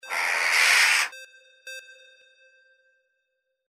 Crow Jackdaw 10A
Stereo sound effect - Wav.16 bit/44.1 KHz and Mp3 128 Kbps
previewANM_CROW_WBHD010A.mp3